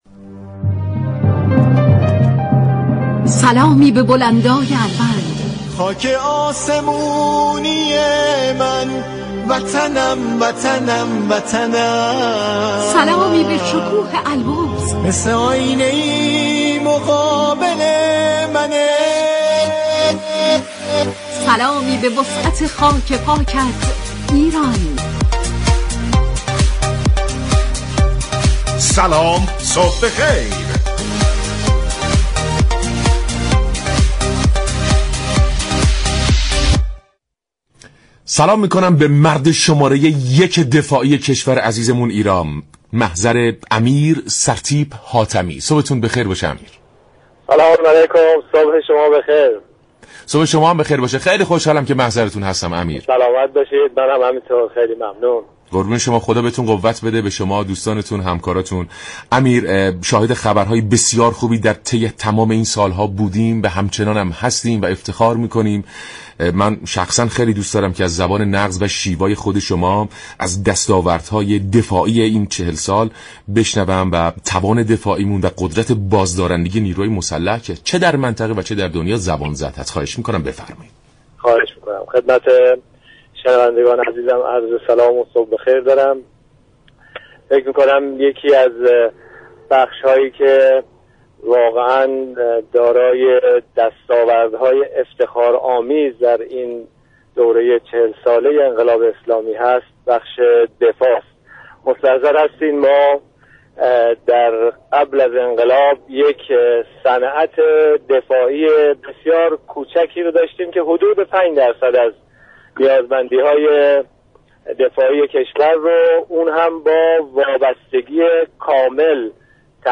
وزیر دفاع و پشتیبانی نیروهای مسلح در برنامه «سلام صبح بخیر» رادیو ایران گفت : مصلی تهران امروز میزبان نمایشگاه صنایع دفاعی كشور است، نمایشگاهی كه اثبات مفهوم ما می توانیم است